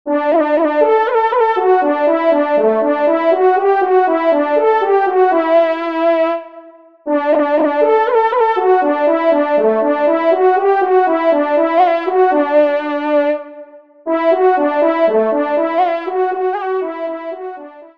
Solo Trompe      (Ton de vénerie)